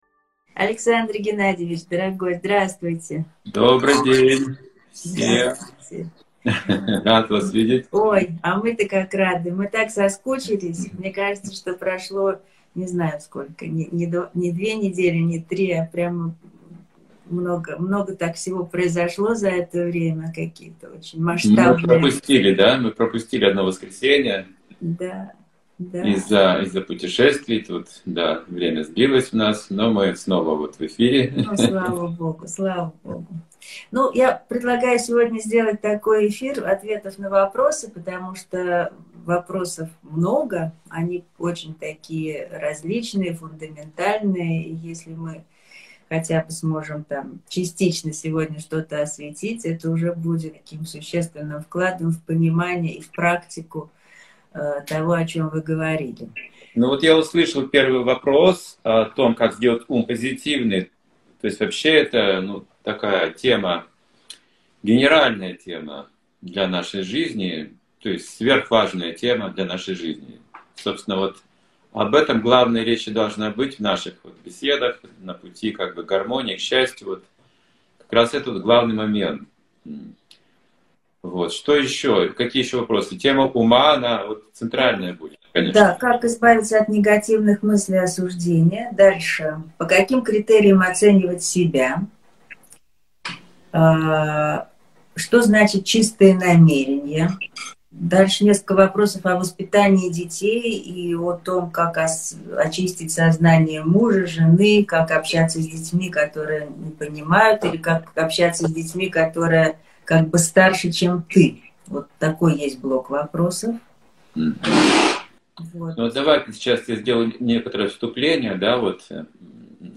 Цикл встреч "Знание о сознании", Ответы на вопросы